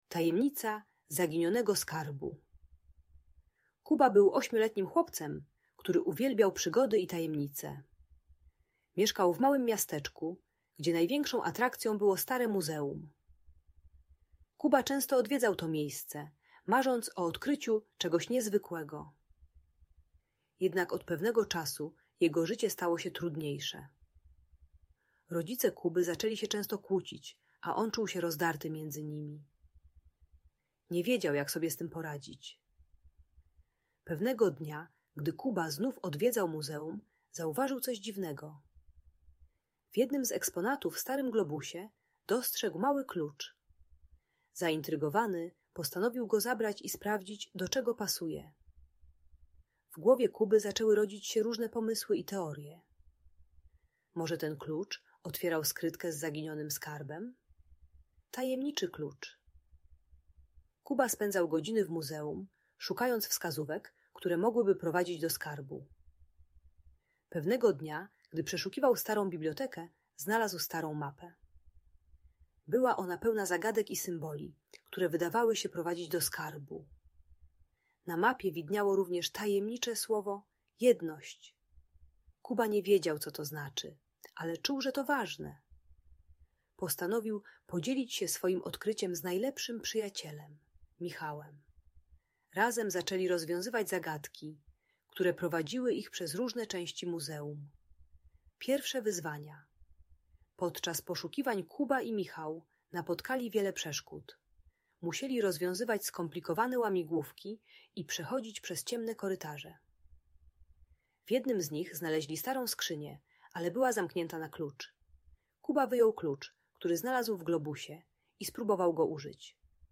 Tajemnicza opowieść o zaginionym skarbie - Audiobajka